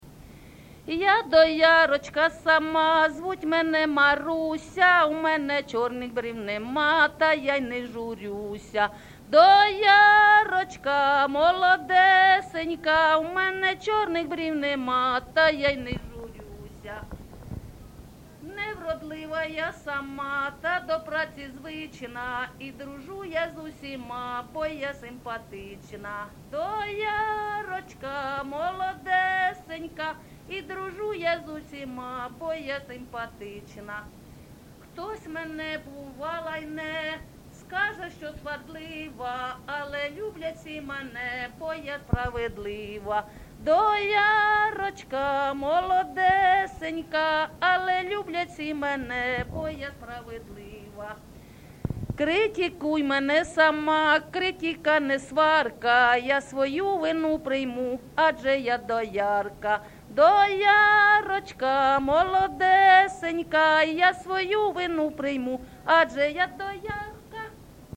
ЖанрКолгоспні, Жартівливі, Сучасні пісні та новотвори
Місце записус-ще Калинівка, Бахмутський район, Донецька обл., Україна, Слобожанщина